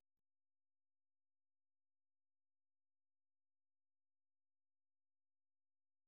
Allegro.
Первая тема отличается двойственностью характера: